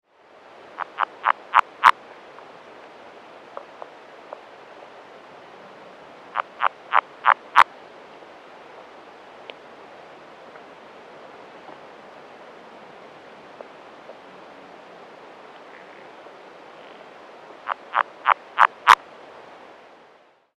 These calls were barely audible in the air, and were very faint underwater, as far as I could determine. 6 or 7 frogs were calling from the area, but the calls were infrequent with long stretches of silence between them.
Sound  This is an unedited 21 second underwater recording of a series of 3 advertisement calls of a single frog.